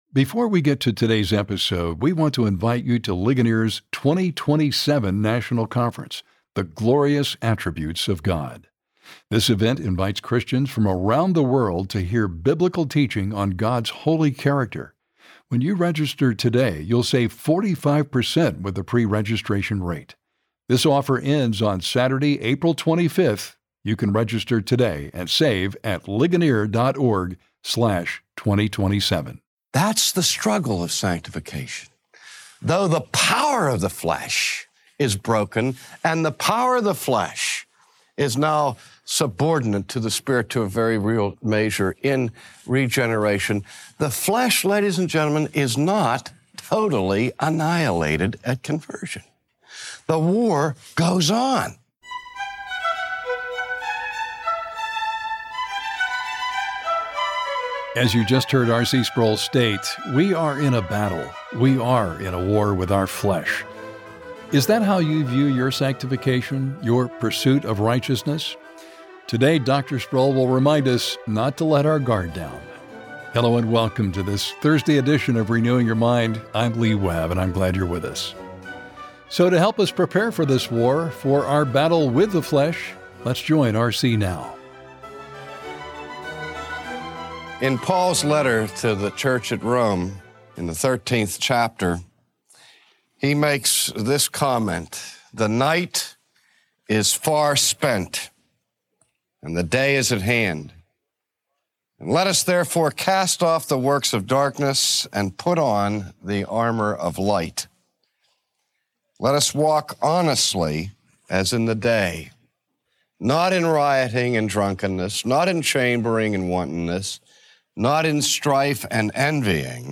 Christians live in conflict with the flesh. But what is "the flesh?" And how can we overcome it? Today, R.C. Sproul explains this biblical term and provides wisdom for defeating this enemy.